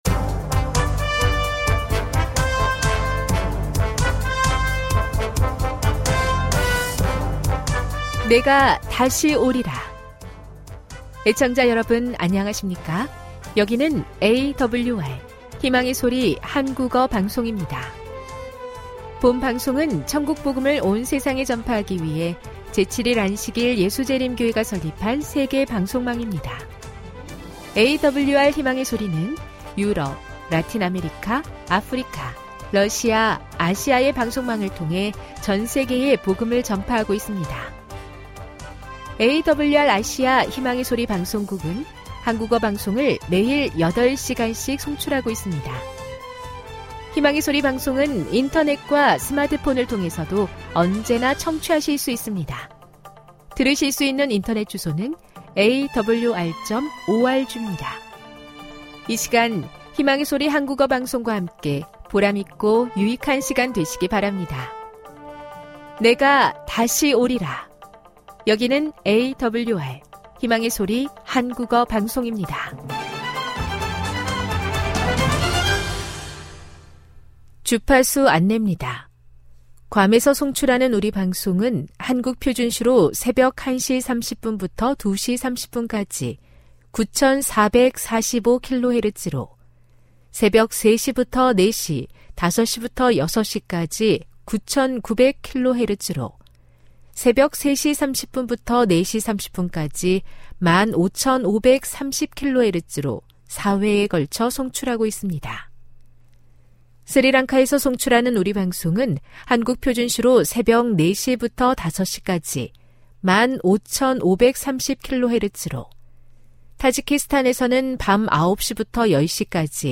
최신 항목은 안식일대예배입니다.